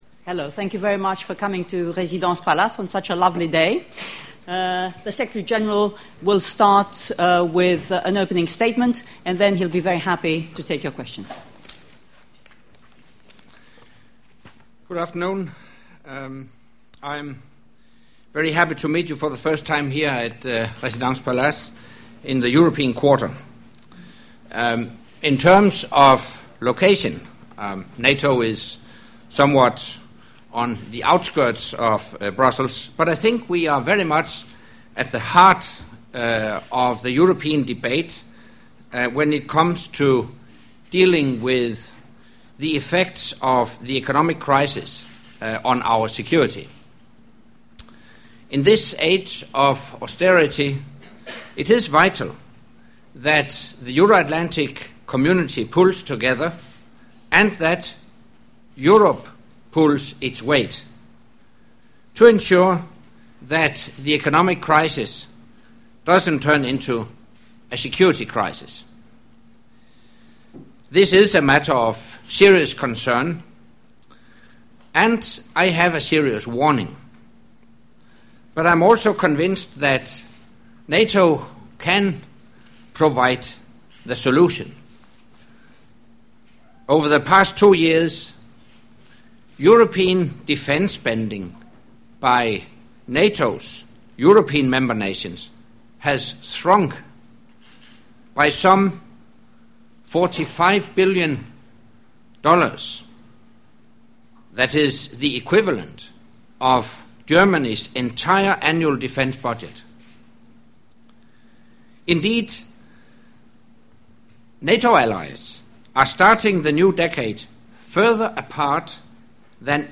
Secretary General’s Monthly Press Conference - 7 February 2011